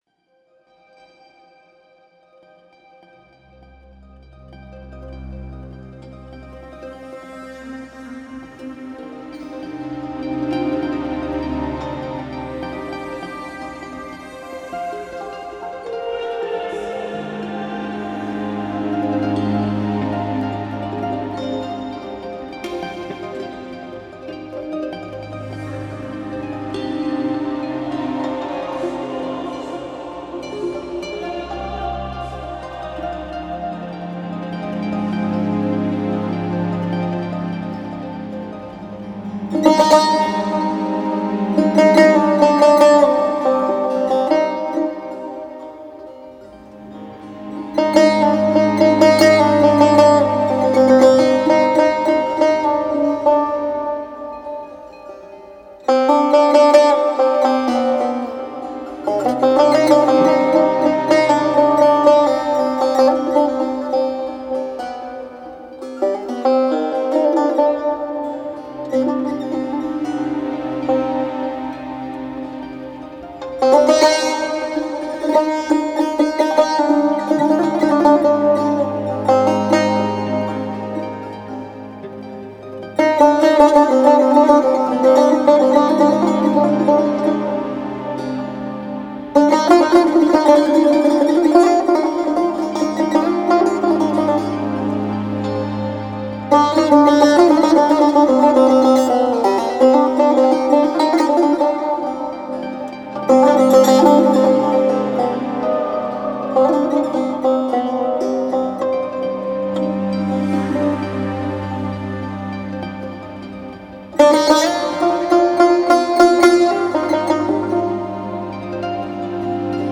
با هنرمندی دو نوازنده؛
تک آهنگ بی کلام
تار، سه‌تار